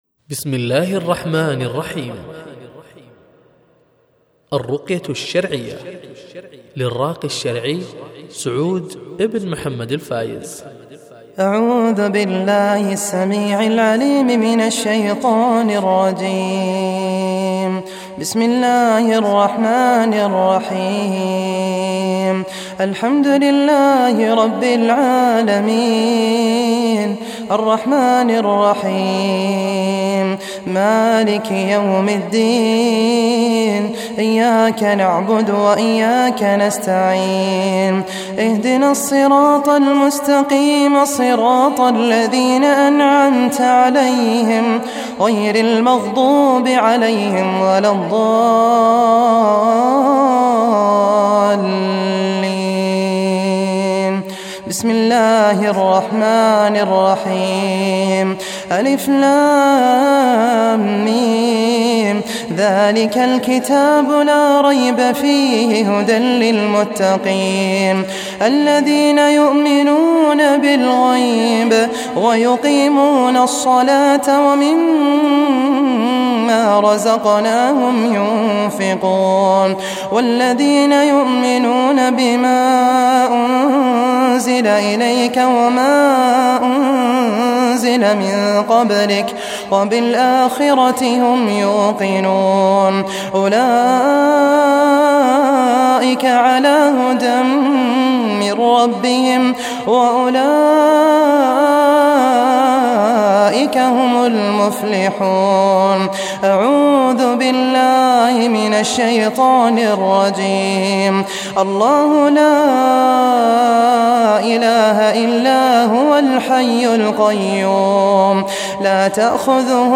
تلاوة للرقية الشرعية الشاملة